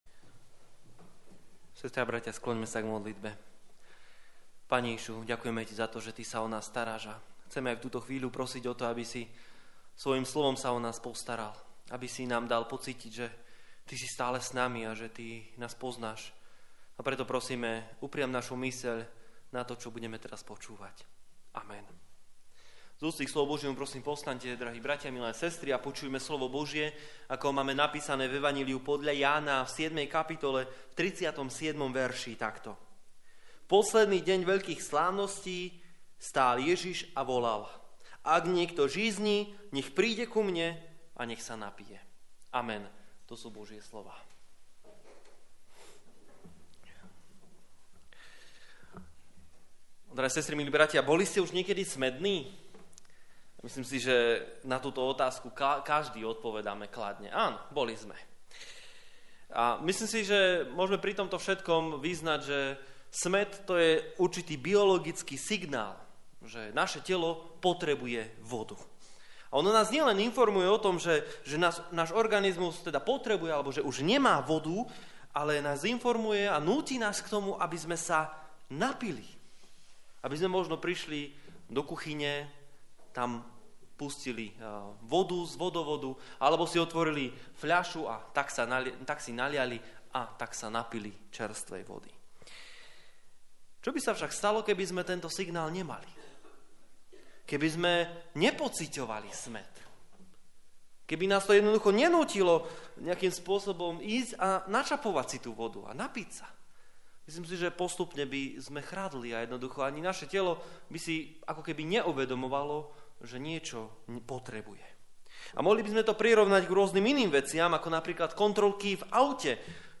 37 Service Type: Služby Božie 14. nedeľa po Svätej Trojici « Život po Božích cestách Nebuď ustarostený